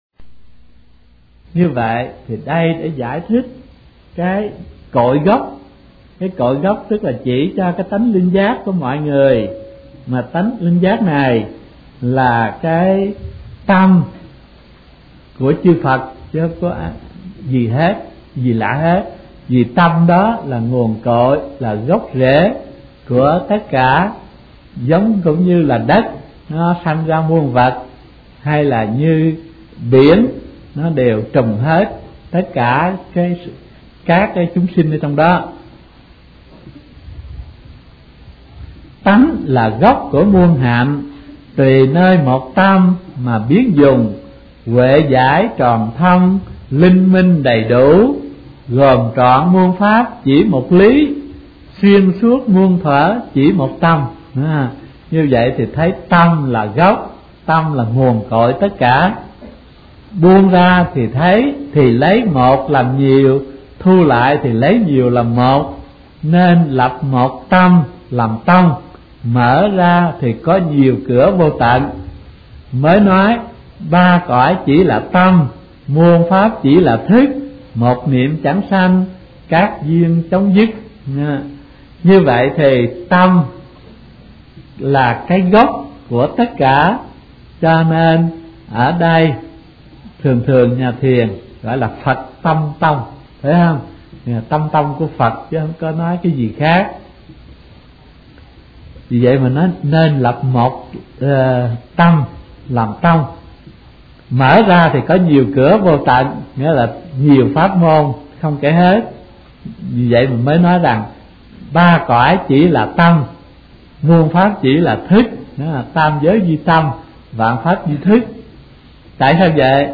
Kinh Giảng Kiến Tánh Thành Phật - Thích Thanh Từ